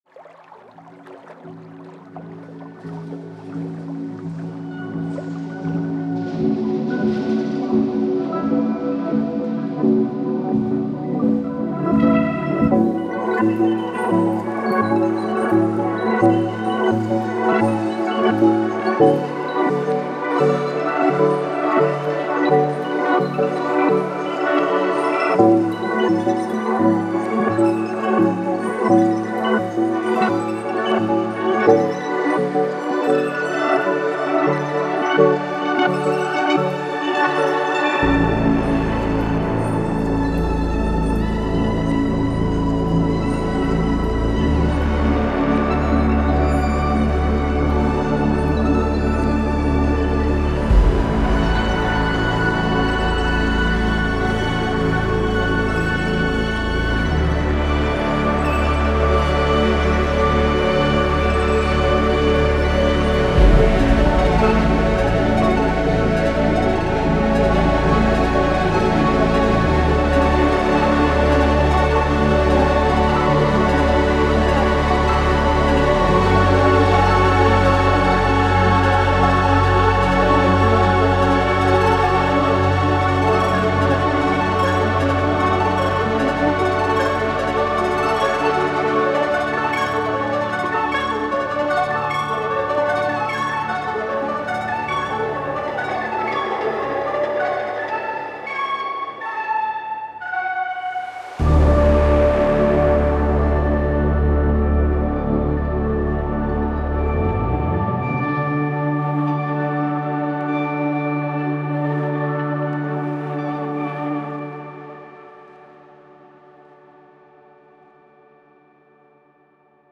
Your tape synth playground
• Authentic modular synth sounds with fast workflow and 492 presets
Evolving modular tape synthesizers